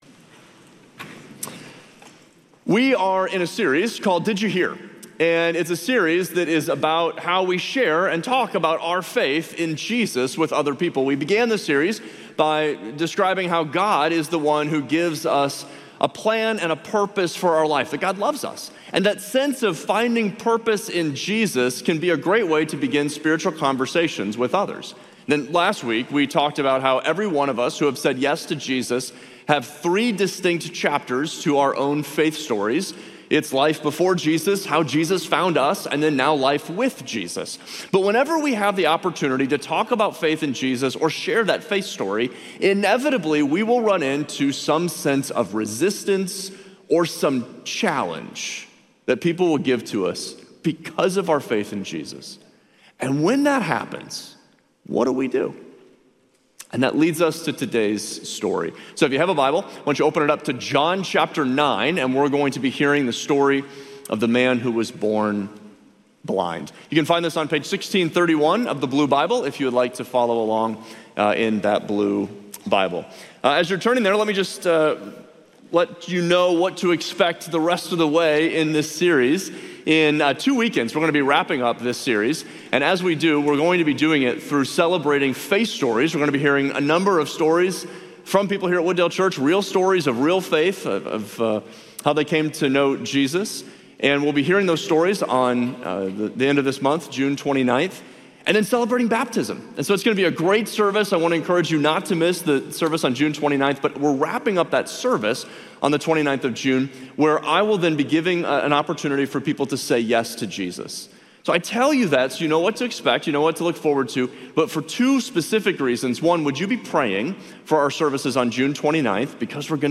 Share this Sermon Facebook Twitter Previous Jesus Knows Me and Still Loves Me Next Receive It!